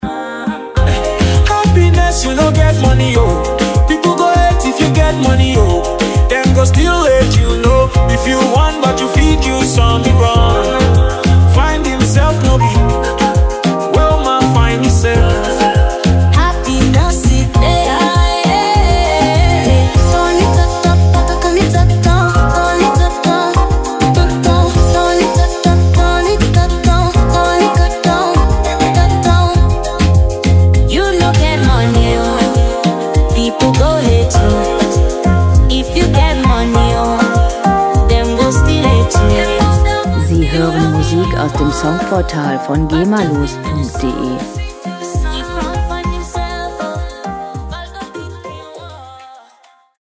World Pop Music aus der Rubrik "Weltenbummler"
Musikstil: World Music
Tempo: 100 bpm
Tonart: F-Moll
Charakter: lebensfroh, munter